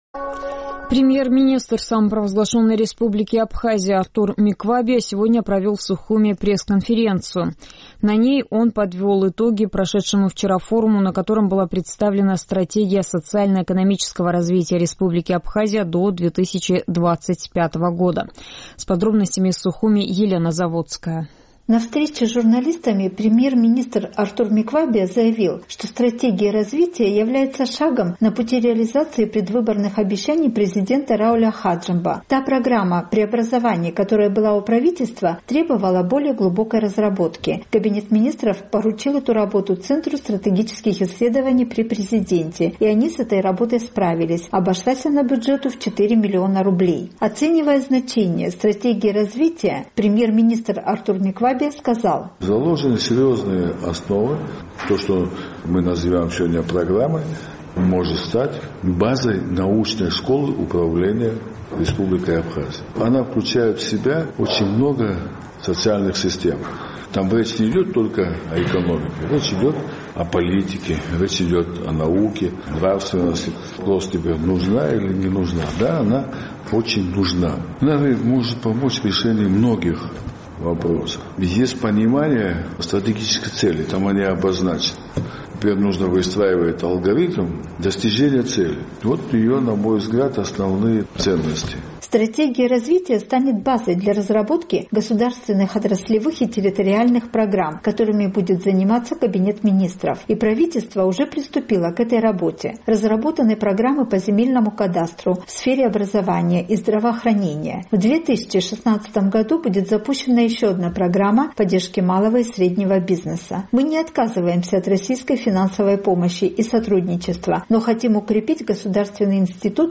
Премьер-министр Абхазии Артур Миквабия сегодня на пресс-конференции подвел итоги прошедшего вчера в Сухуме форума.